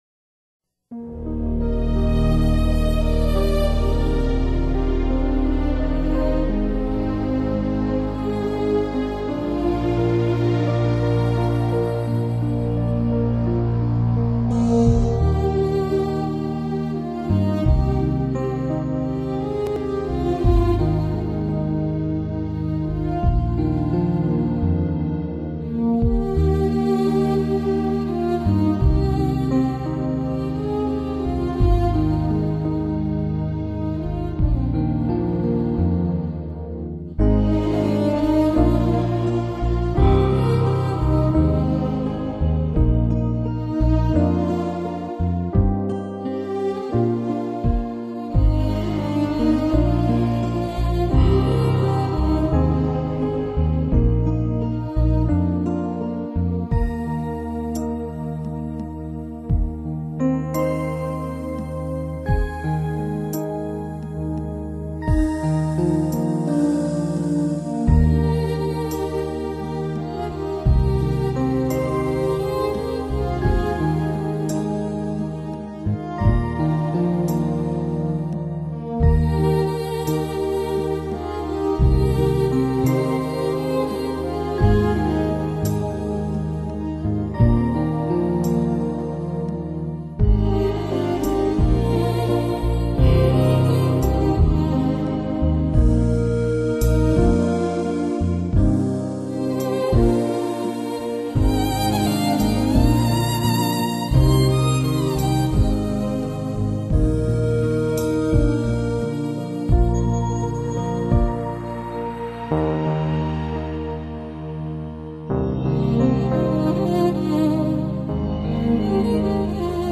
柔和清涼的声音中，听着听着就会感觉到心境好像处在充满清香的莲花大海之中，
是那么的温柔，那么的清涼，那么的清明，心情马上就会平静下來。